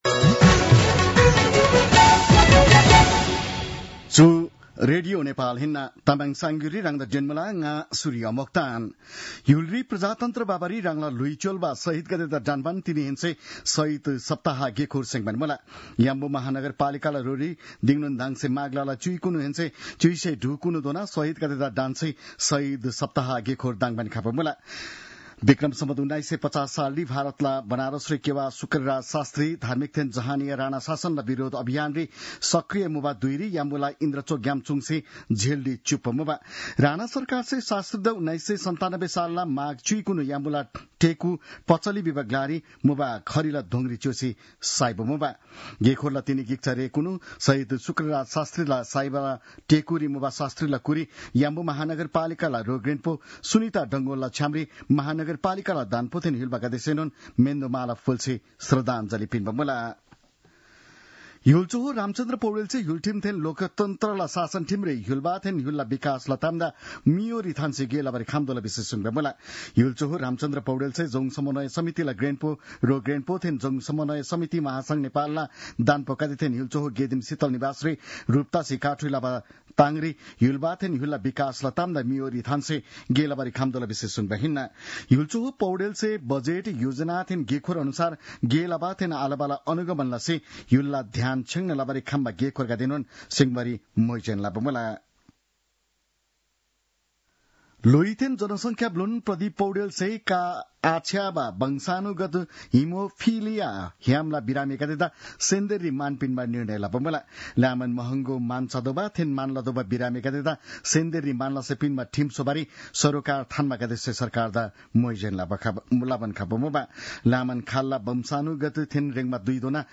तामाङ भाषाको समाचार : ११ माघ , २०८१